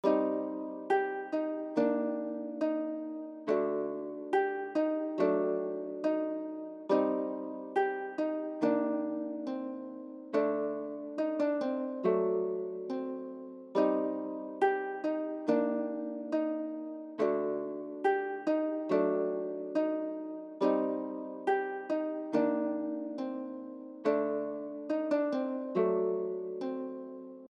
p - carousel 140 BPM.mp3